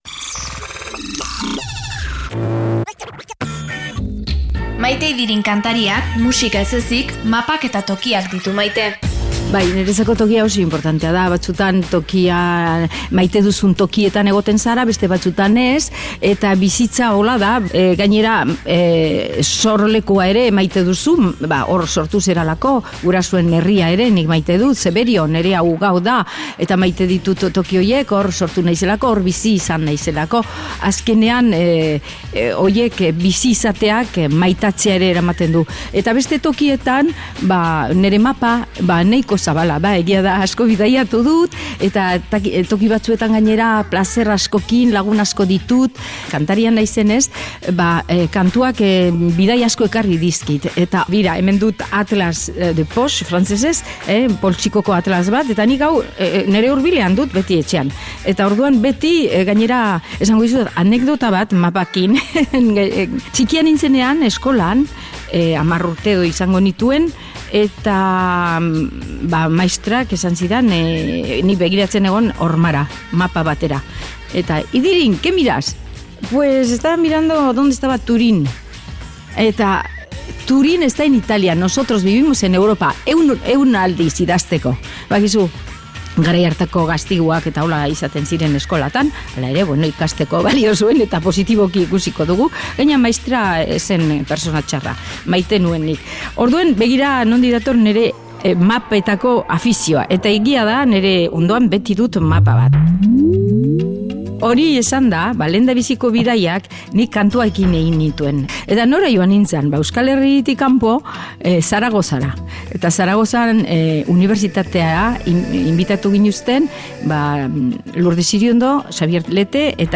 Play Rate Listened List Bookmark Get this podcast via API From The Podcast Gure herriaren jakintzaz gure fonotekan aukeratutako edukien artetik aukeratu ditugu zuretzat perla hauek elkarrizketa formatuko podcastetan Join Podchaser to...